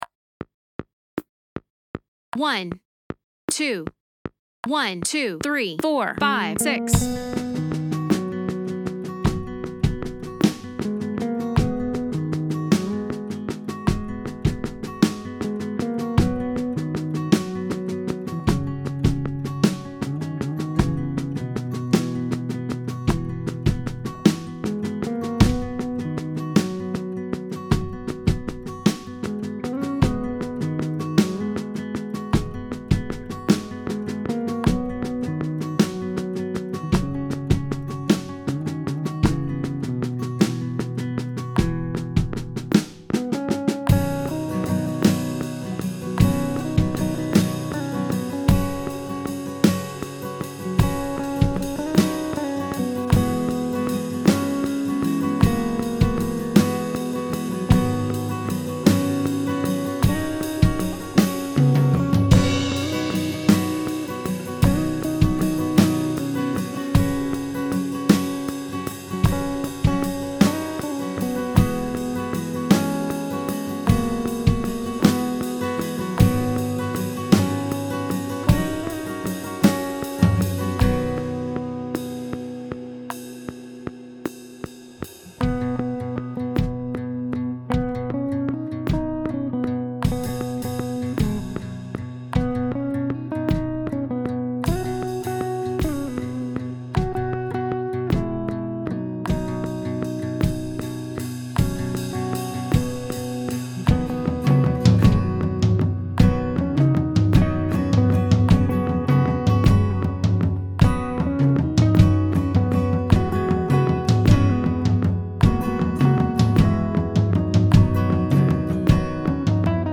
Accompaniment